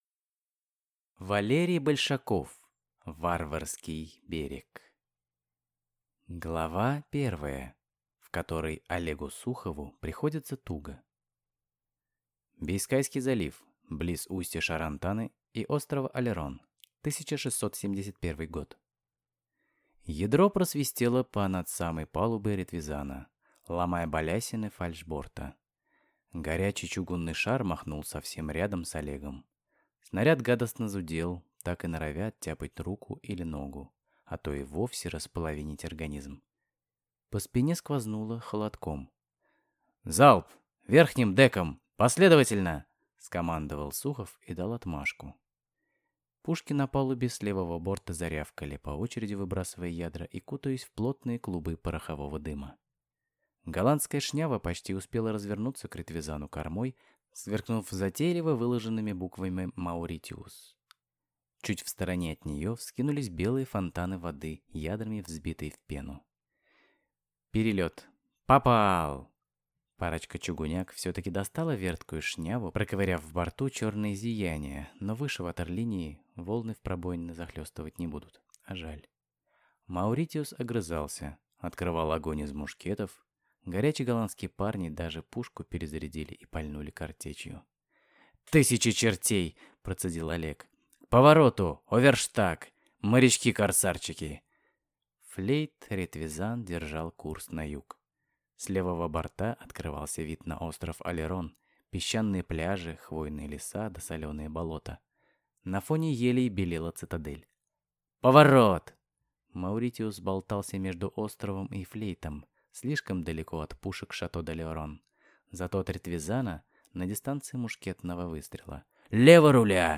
Аудиокнига Варварский берег | Библиотека аудиокниг